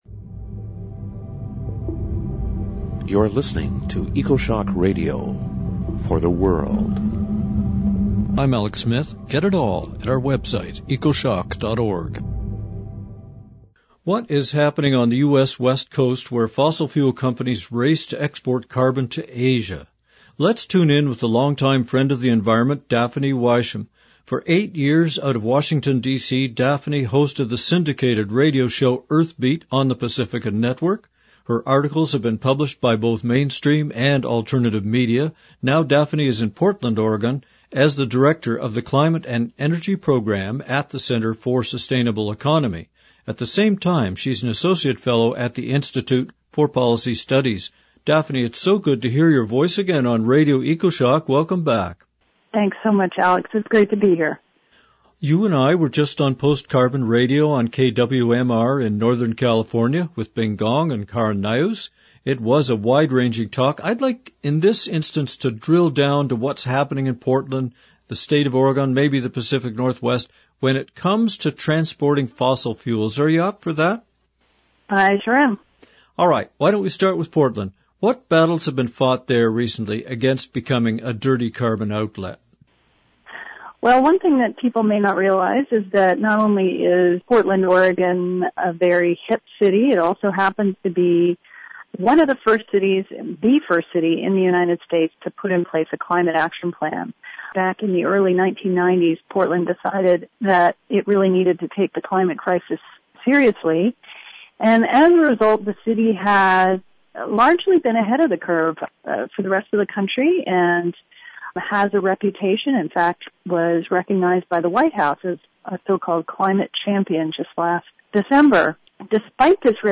All that plus two climate songs.